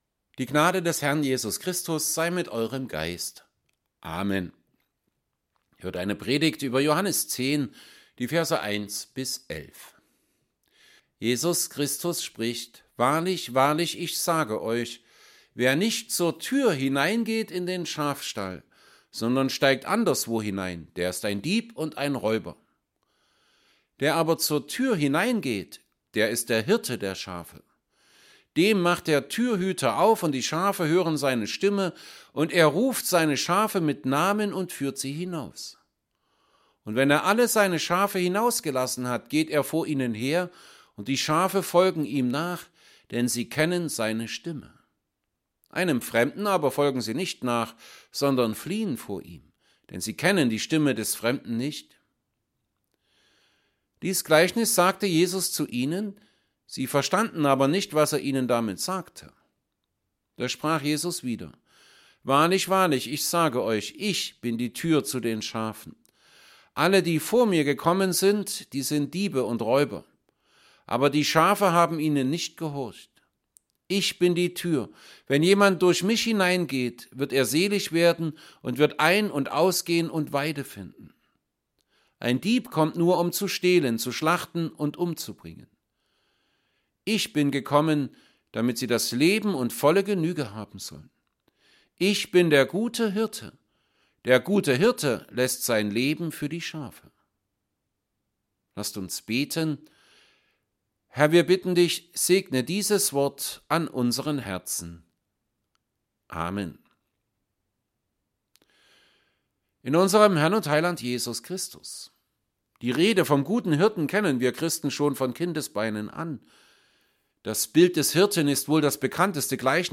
Evangelienpredigten | St. Paulusgemeinde Saalfeld
Predigt_zu_Johannes_10_1b11.mp3